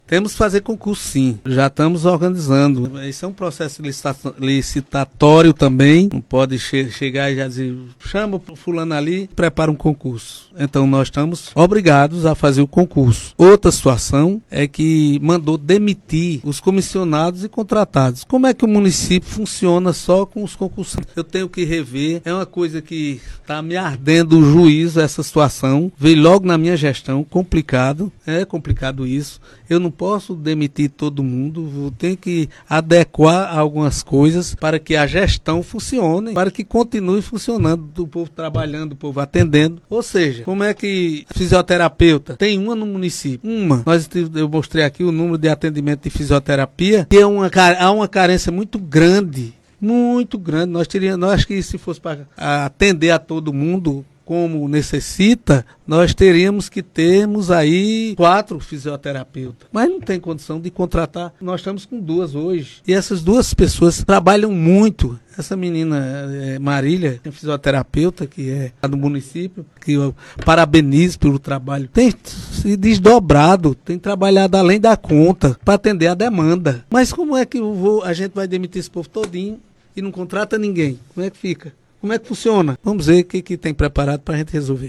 O prefeito de Santa Terezinha (PB), Arimateia Camboim (Republicanos), foi entrevistado na sexta-feira (24), no Conexão com a Notícia, transmitido pela Rádio Conexão FM de Santa Terezinha.